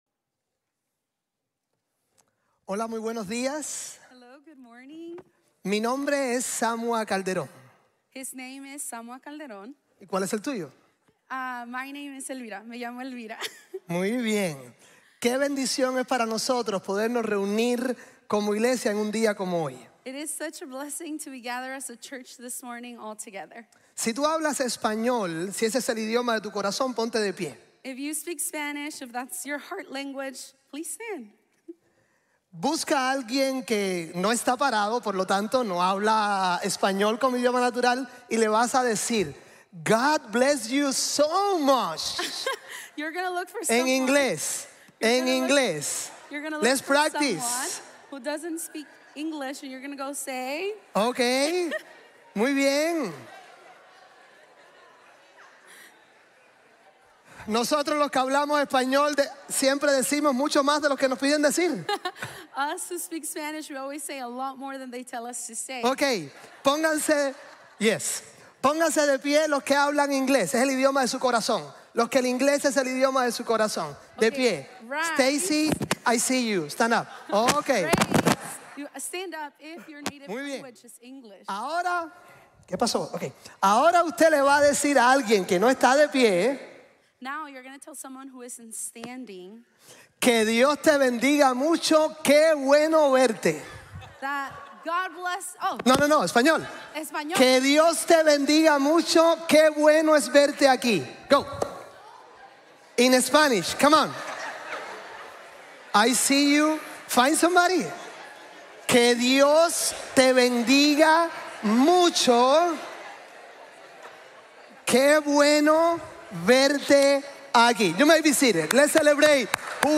La mayor esperanza de la vida | Sermon | Grace Bible Church